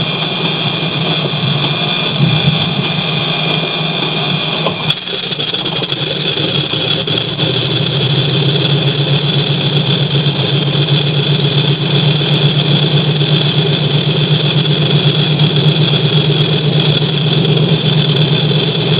DC-3 Sound Files
Number 1 (right) engine start up (recorded from outside).